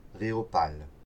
Rehaupal (French pronunciation: [ʁe.opal]
Rehaupal_(Vosges).ogg.mp3